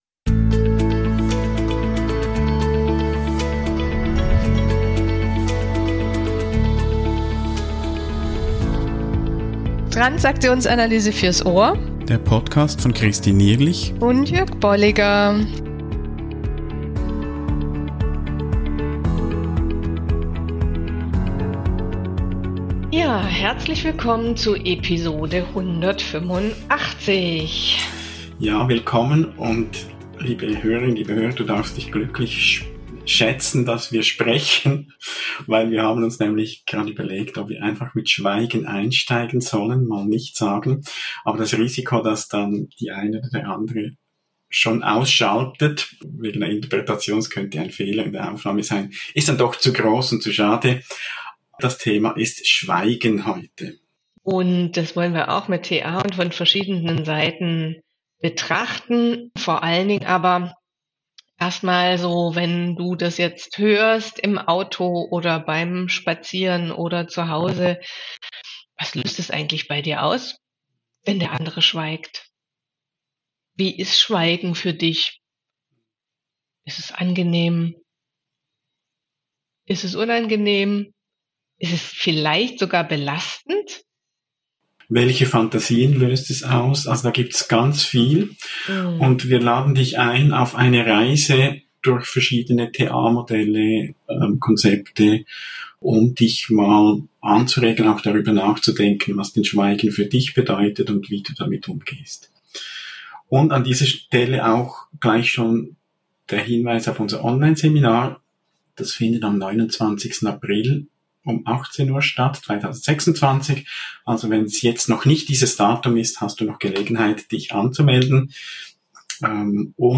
Gespräche über Modelle und Konzepte der Transaktionsanalyse und deren Anwendung